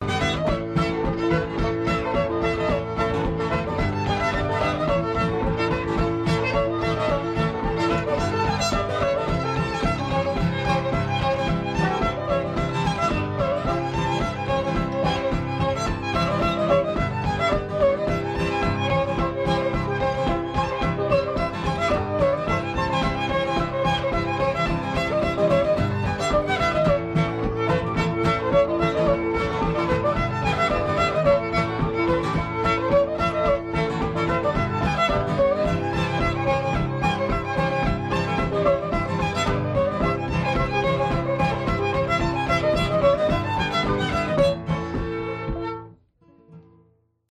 gtr